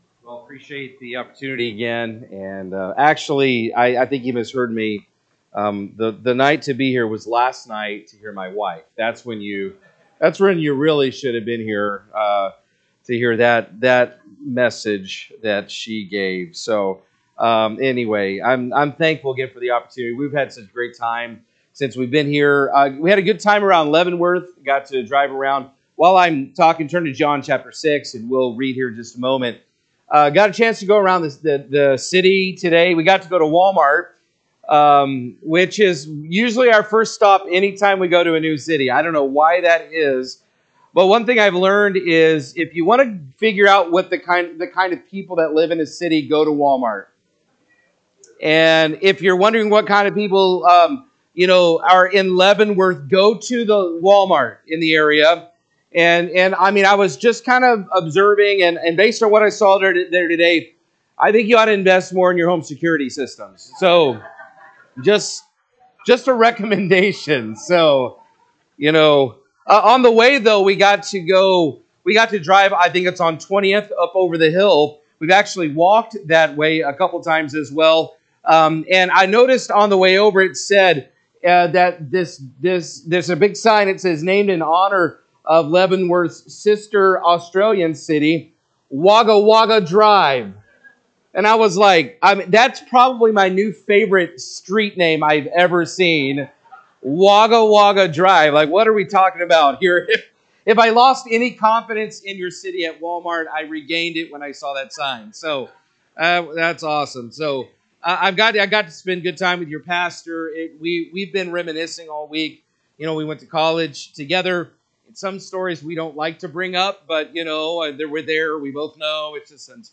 Mission Revival 2025